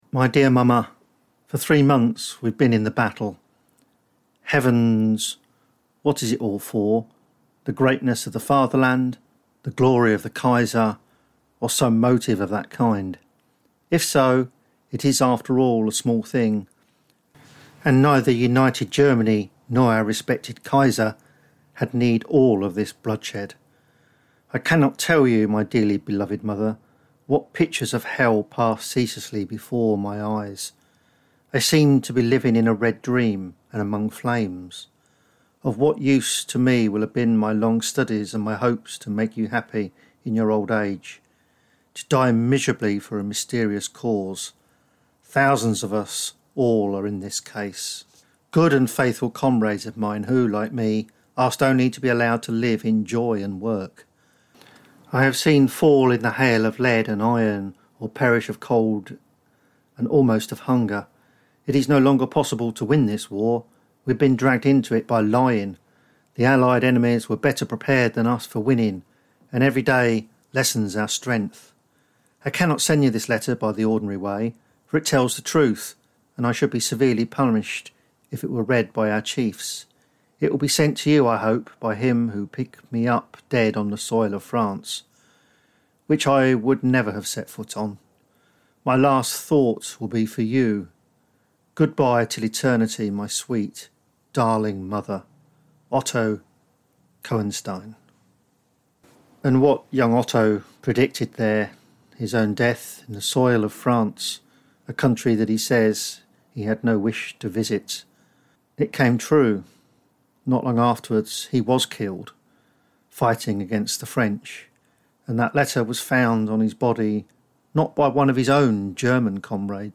And we hear a Victoria Cross recipient singing, a few months before he died on the Somme.